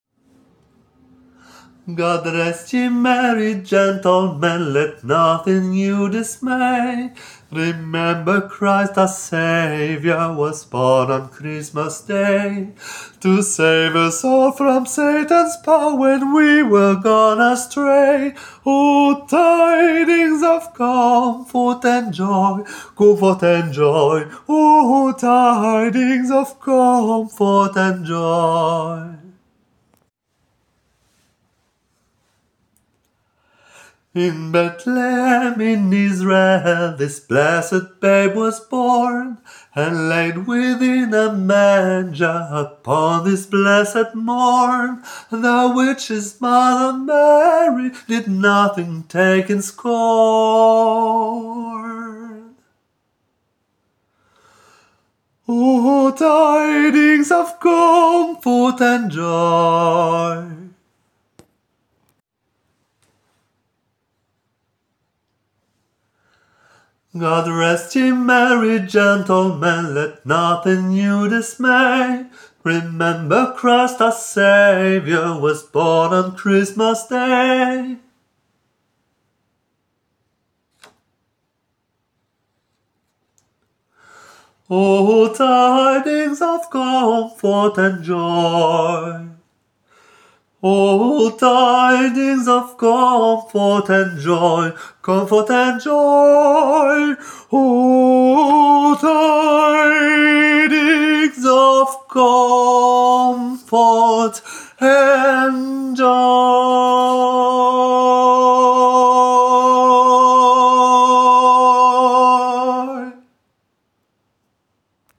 tenor
GOD-REST-YE-MERRY-GENTLEMEN-tenor.aac